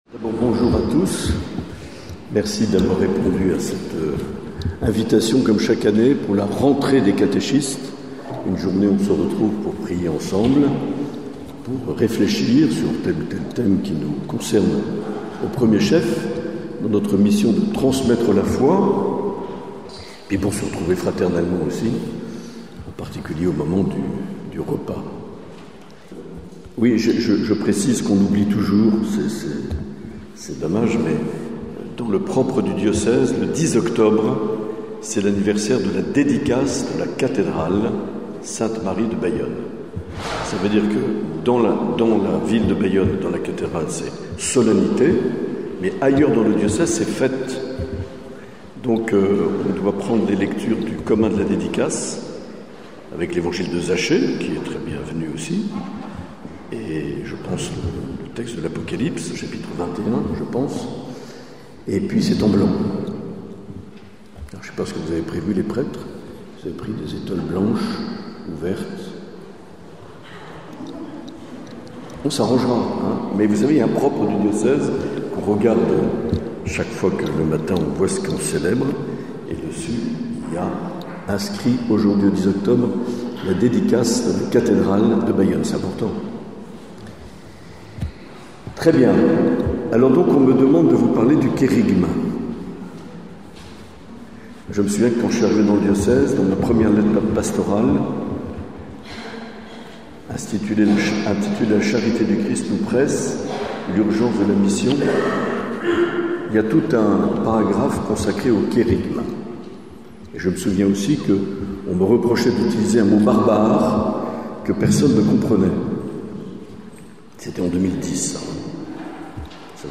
Enseignement sur le Kérygme de Mgr Marc Aillet
Enregistrement réalisé lors de la journée "Catéchèse en fête" à Salies-de-Béarn le 10 octobre 2023